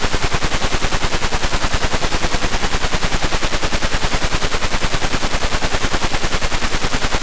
File:Chinese radar 160kHz wide WFM.mp3 - Signal Identification Wiki
Chinese_radar_160kHz_wide_WFM.mp3